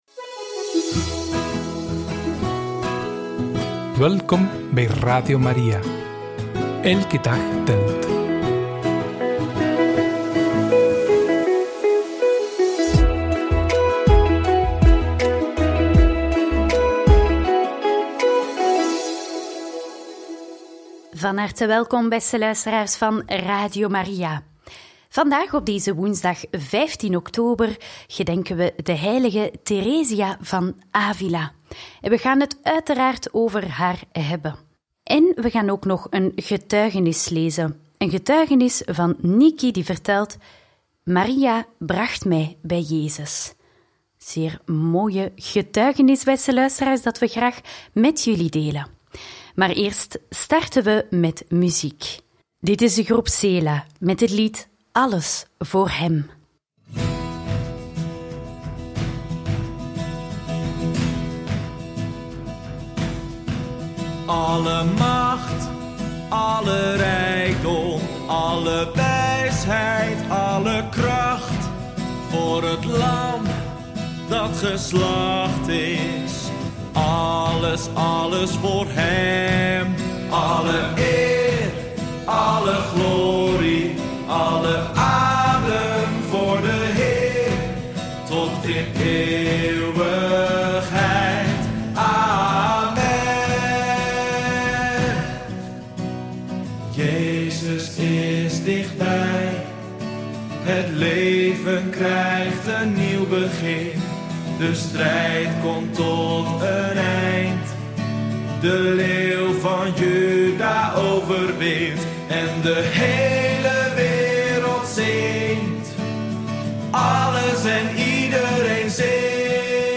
Getuigenis